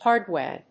/ˈhɑˌrdwe(米国英語), ˈhɑ:ˌrdweɪ(英国英語)/